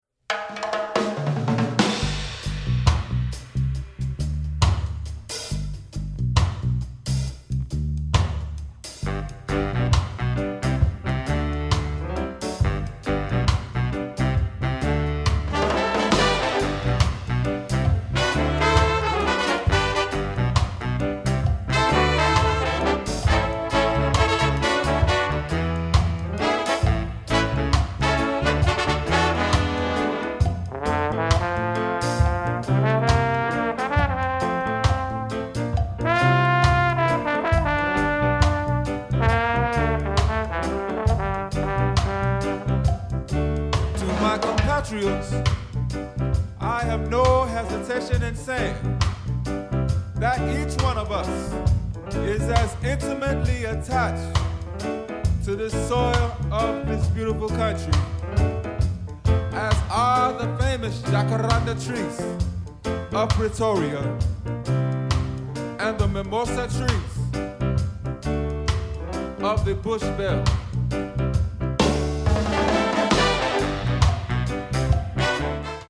Big Band Charts
Solos: trombone, vocal (on bone 2 chair)